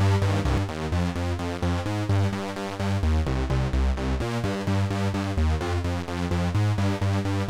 It seems to lose a lot of the hi frequencies.
Both are at -13db.
OT does actually sound like shit in that clip.
these clips aren’t even close to being level-matched